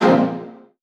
CartoonGamesSoundEffects
Scare_v3_wav.wav